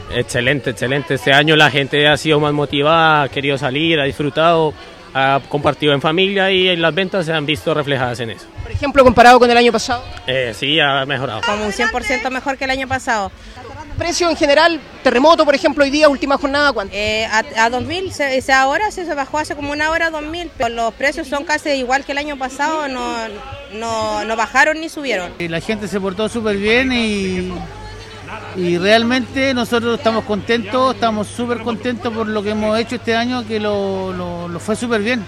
Radio Bío Bío llegó al lugar para conocer las impresiones en los instantes finales de los festejos.
cu-saval-2-locatarios.mp3